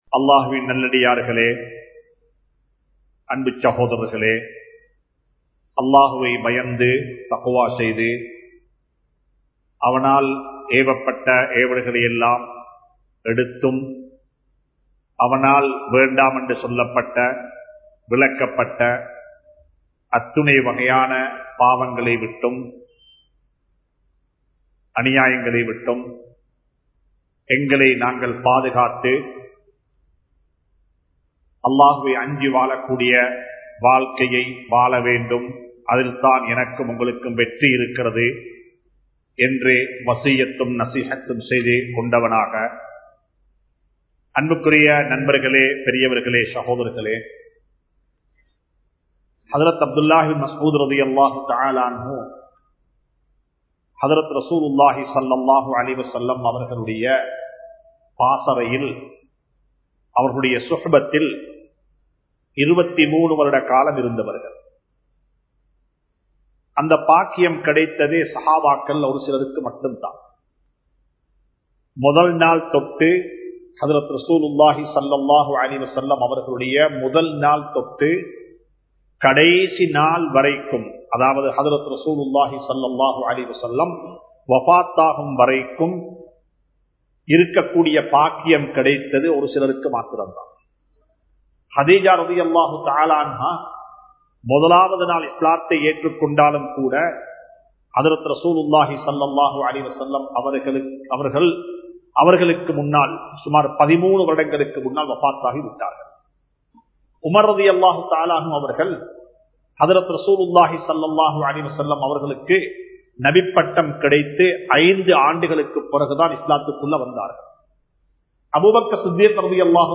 Nadikkum Manitharhal (நடிக்கும் மனிதர்கள்) | Audio Bayans | All Ceylon Muslim Youth Community | Addalaichenai